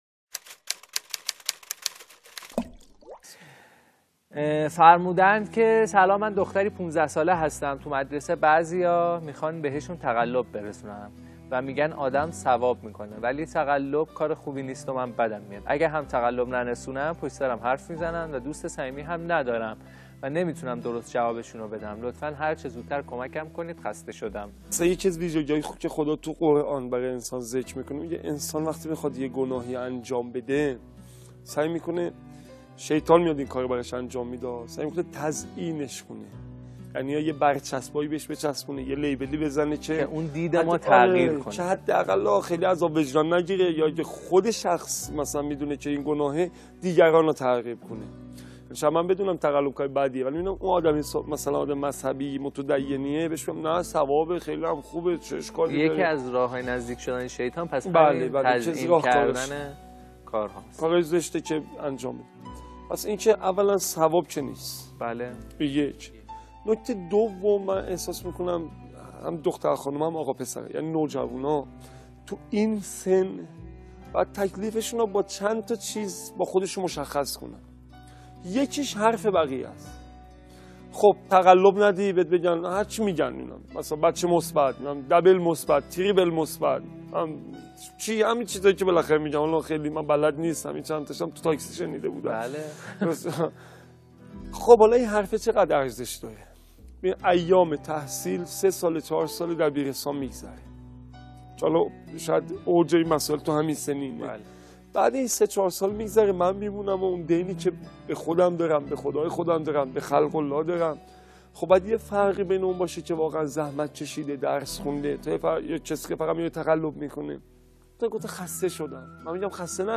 سخنرانی | تزئین گناهان توسط شیطان